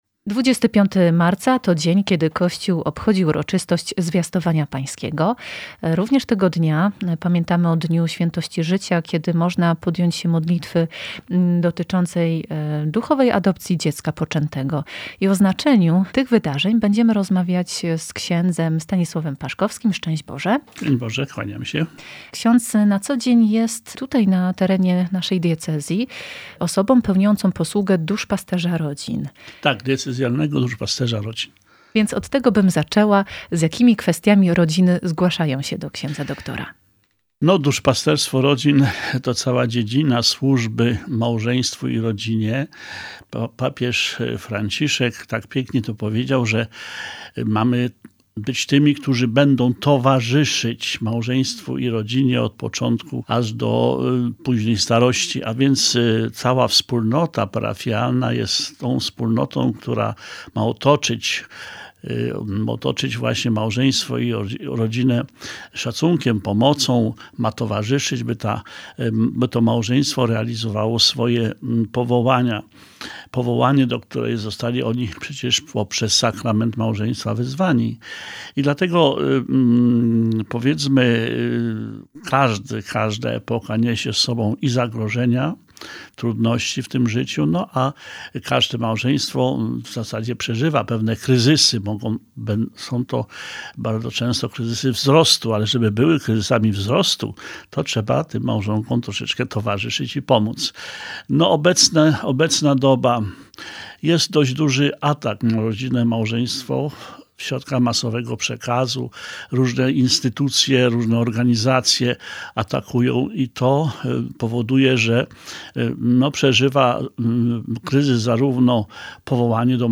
W jednej z ostatnich audycji rozmawialiśmy o towarzyszeniu rodzinom, o świętości i ochronie życia od poczęcia do momentu naturalnej śmierci [marzec 2021].